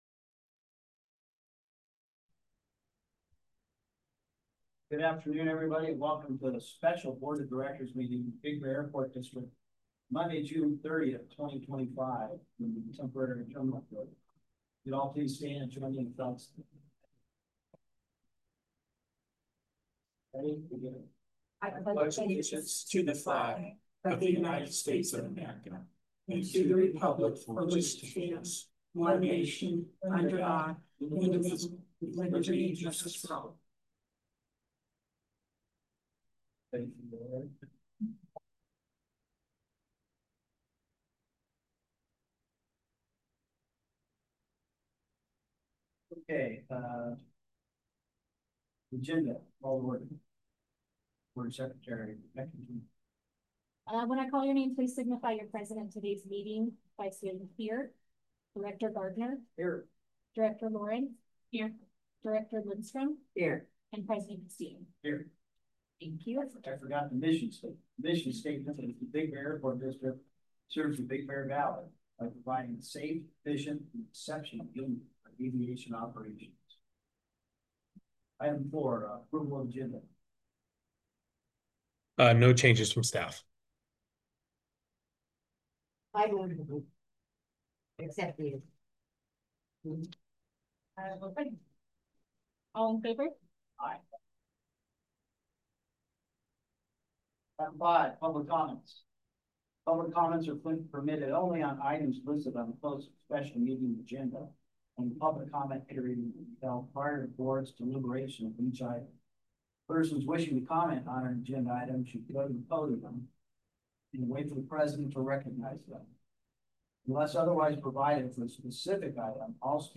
The Board of Directors of the Big Bear Airport District meet on the second Wednesday of each month at 4:00 p.m. in the District Board Room at the…
Board Regular Meeting